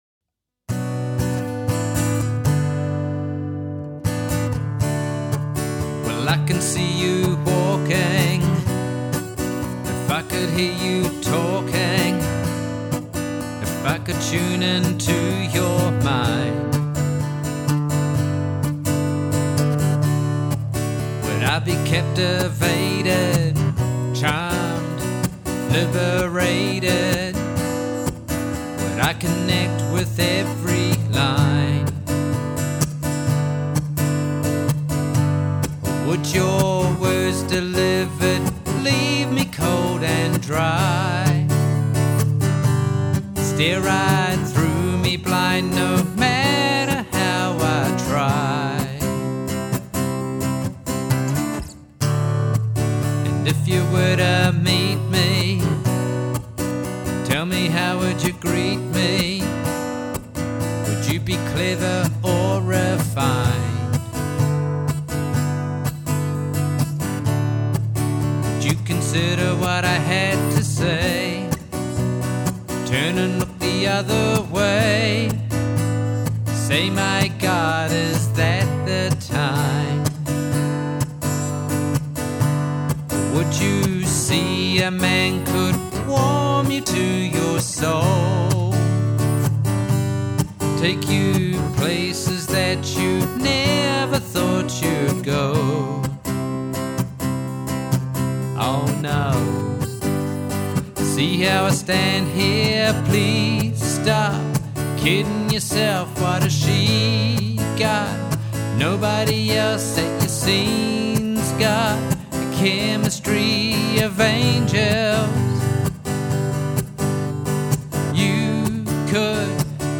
Recorded in Westmere, Auckland, summer 2023 Tracklist